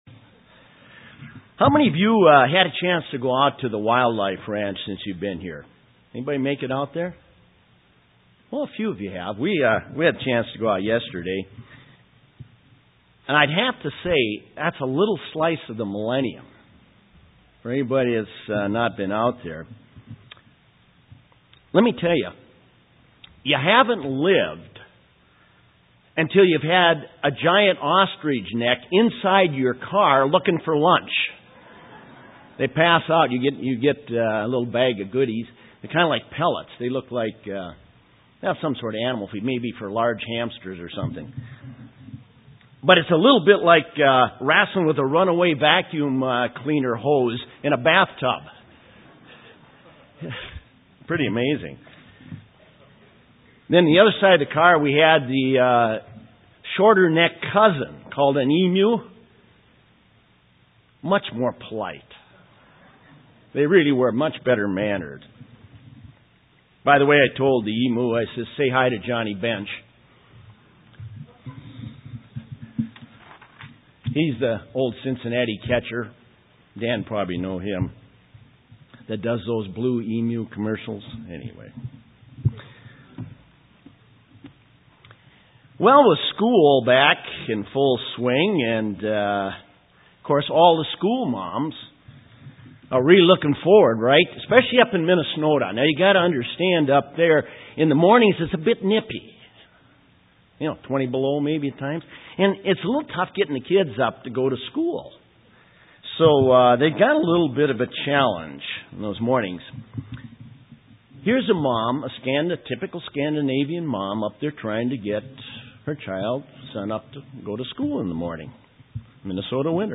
Day six FOT New Braunfels.
Given in San Antonio, TX
UCG Sermon Studying the bible?